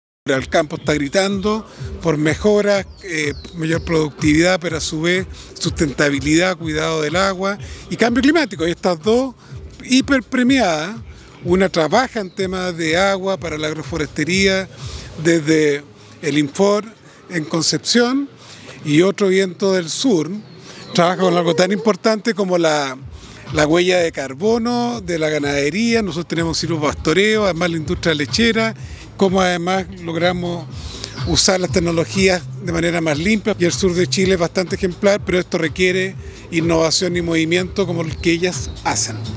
Ministro de Agricultura